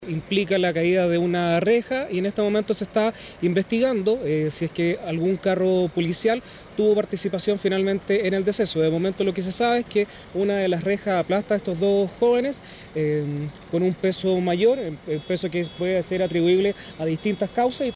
Según lo dicho por el fiscal de Flagrancia Oriente, Francisco Mores, la investigación determinará la presunta responsabilidad del carro policial en la muerte de ambas personas.